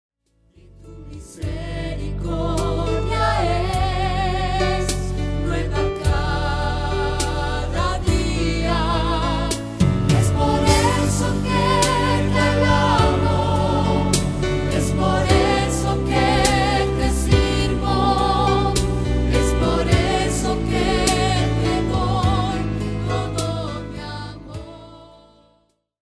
álbum clásico de adoración